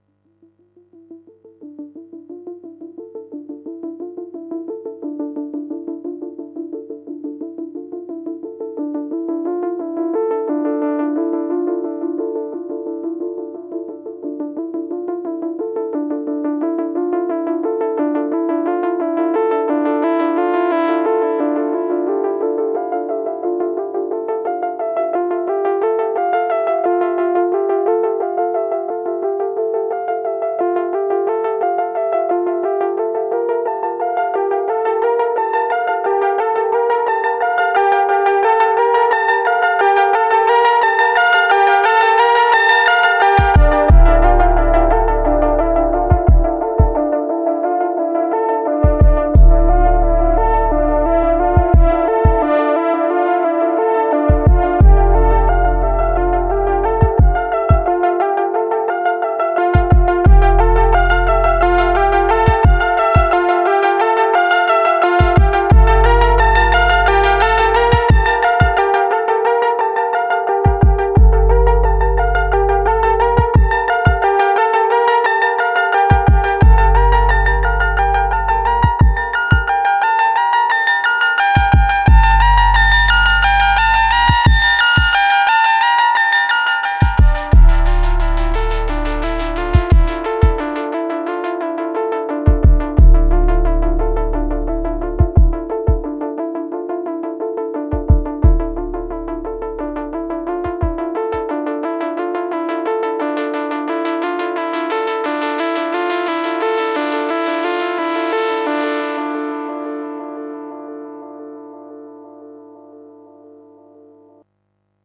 digitakt sequencing modular